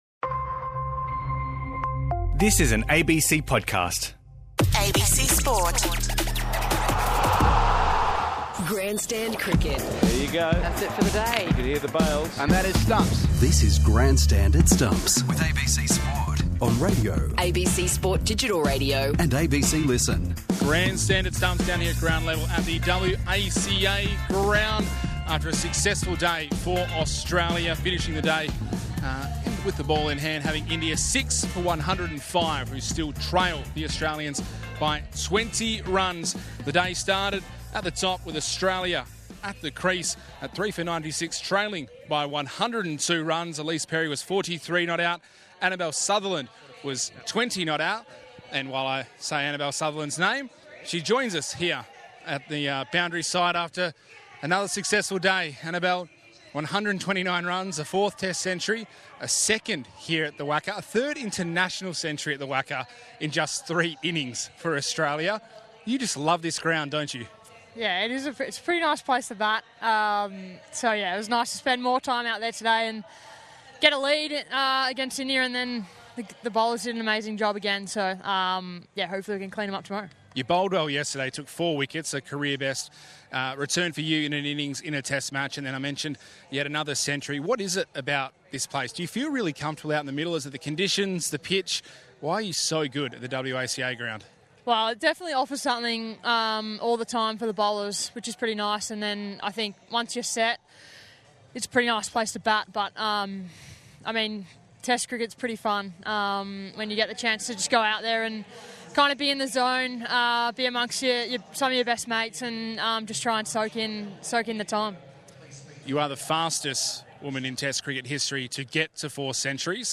Plus, they chat to Aussie superstar Annabel Sutherland after her fourth test century and two wickets.